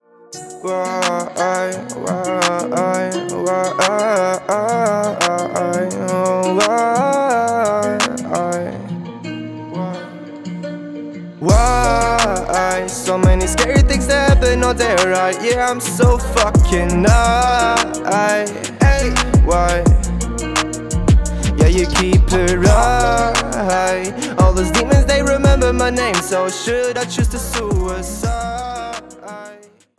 Vocal / Editieren